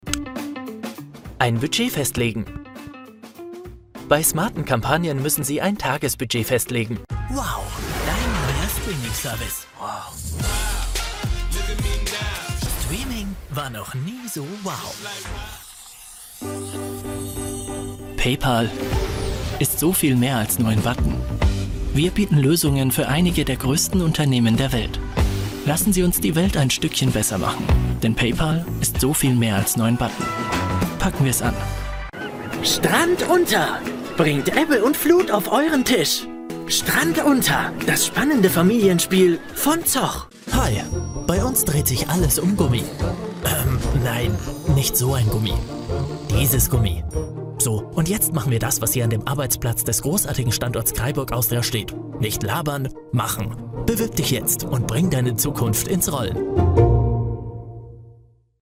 Teenager, Young Adult, Adult
german | natural
COMMERCIAL 💸
cool
warm/friendly